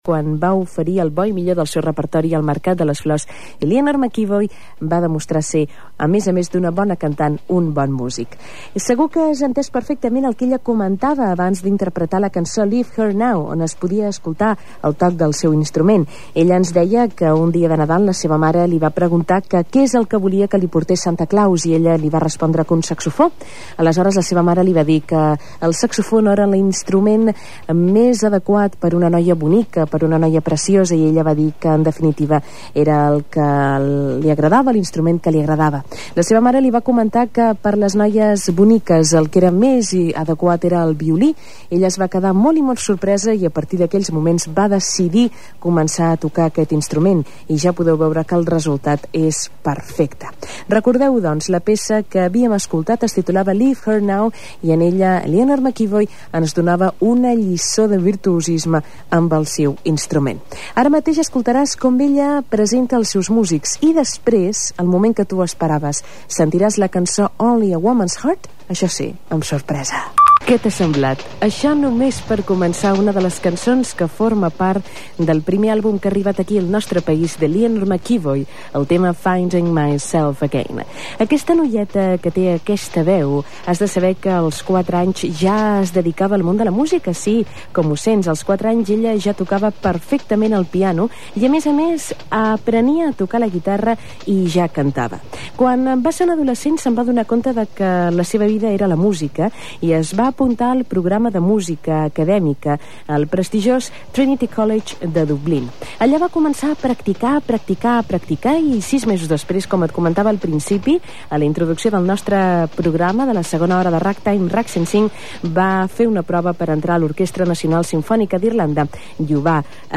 Crònica del concert que va realitzar a Barcelona Eleanor McEvoy i presentació de temes enregistrats en el concert.
Musical